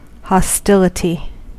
Ääntäminen
Synonyymit war fighting opposition animosity antipathy combat antagonism hatred enmity froideur Ääntäminen US UK : IPA : /hɒˈstɪlᵻti/ Haettu sana löytyi näillä lähdekielillä: englanti Käännös Substantiivit 1.